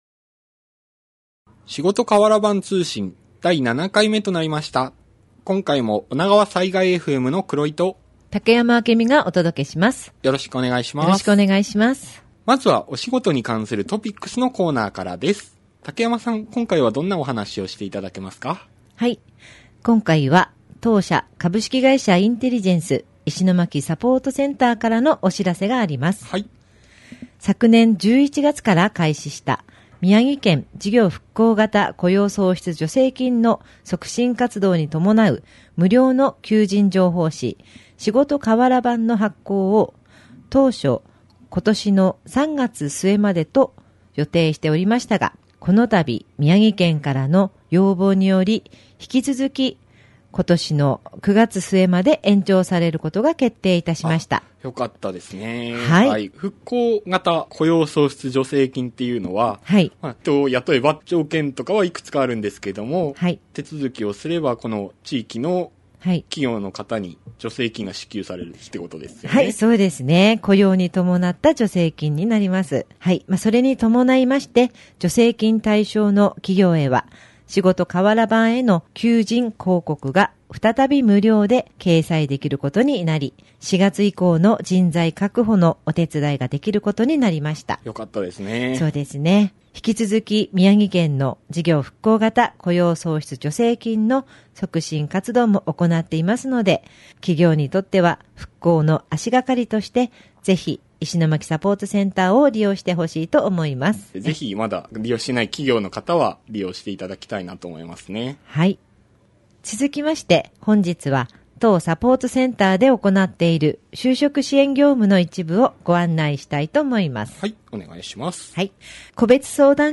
【企業インタビュー】